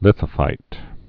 (lĭthə-fīt)